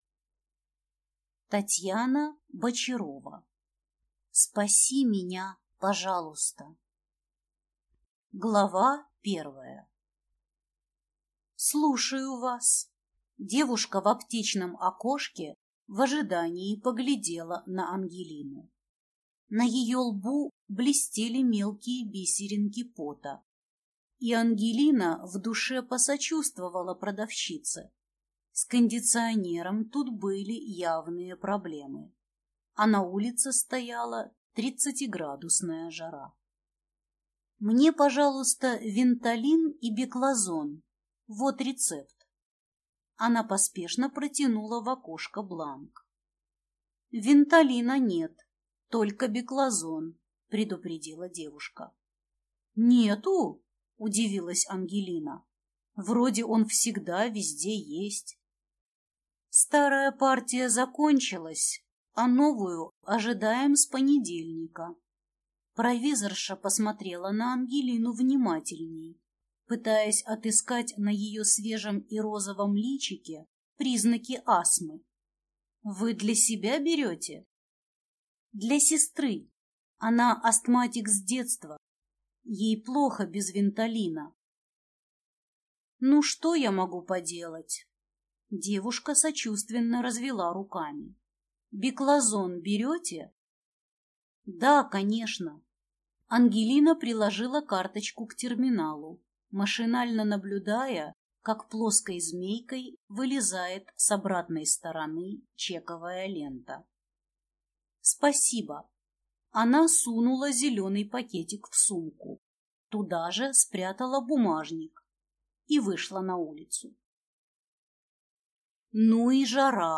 Аудиокнига Спаси меня, пожалуйста!